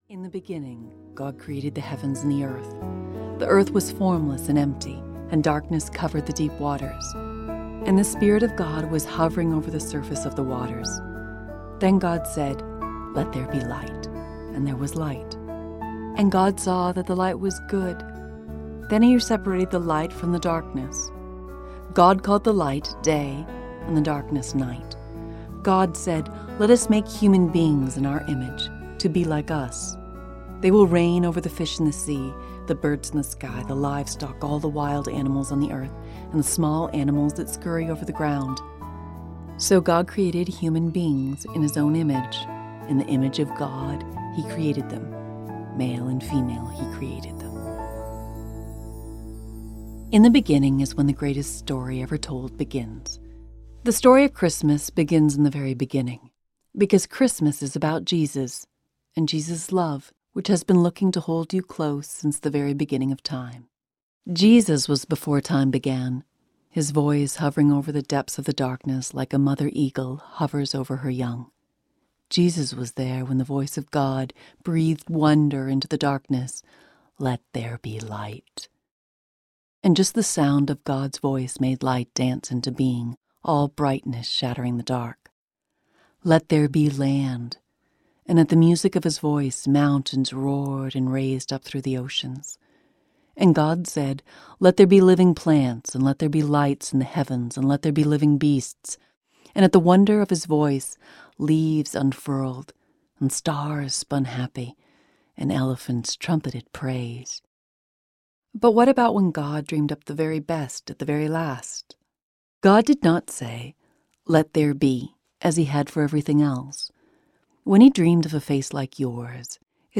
Unwrapping the Greatest Gift: A Family Celebration of Christmas Audiobook
Narrator